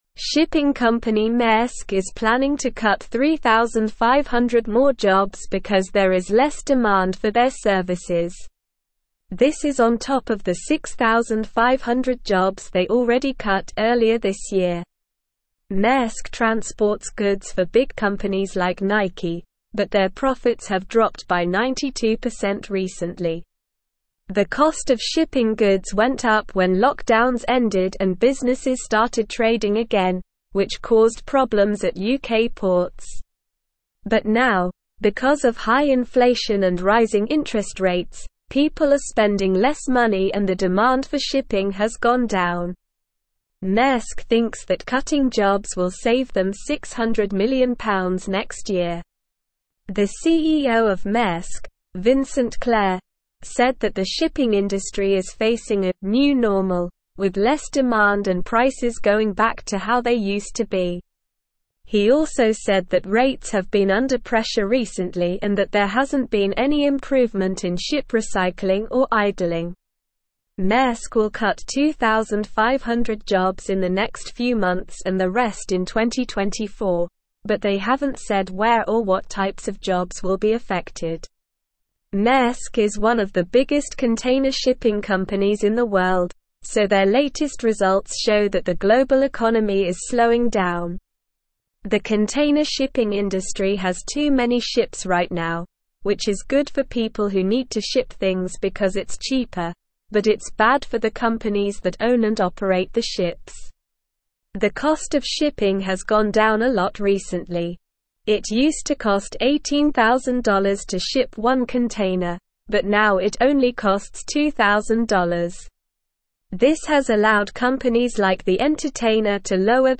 Slow
English-Newsroom-Upper-Intermediate-SLOW-Reading-Maersk-to-Cut-3500-Jobs-Amid-Lower-Demand.mp3